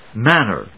/mˈænɚ(米国英語), ˈmænɜ:(英国英語)/